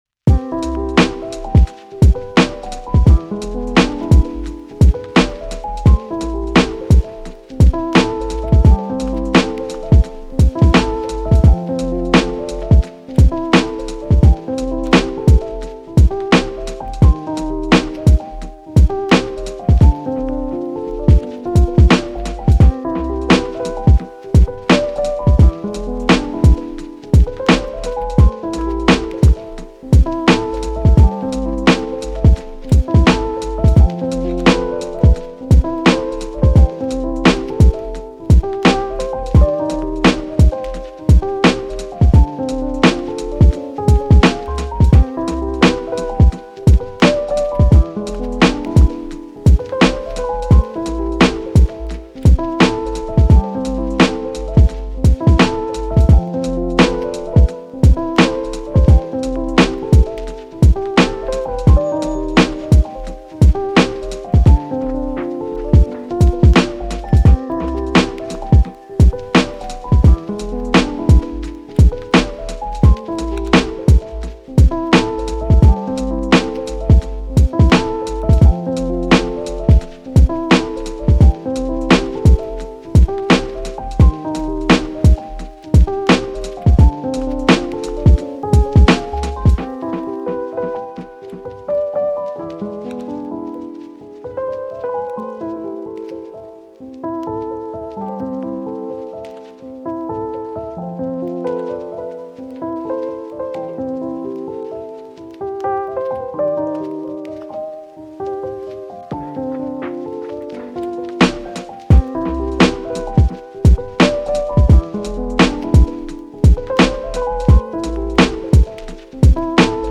チル・穏やか